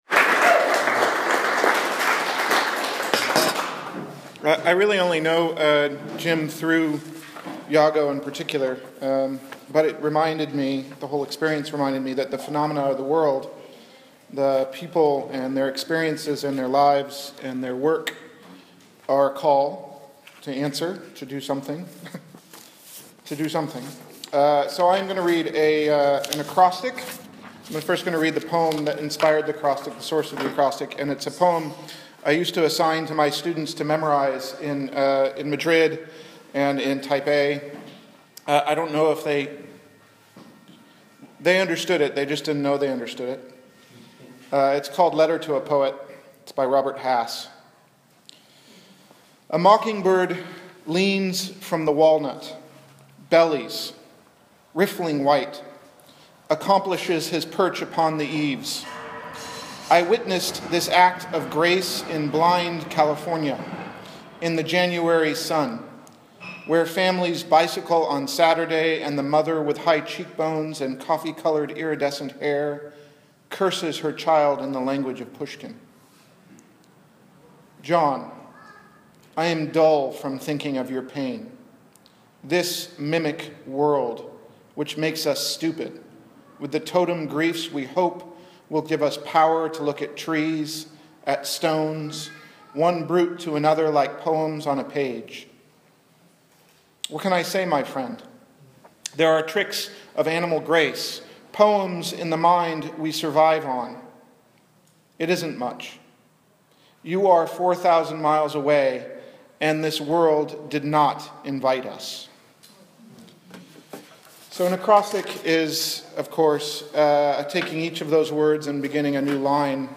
Listen to some of the poems read by the poet, and sign up to get notified when the book is ready, stay up to date on readings and other news.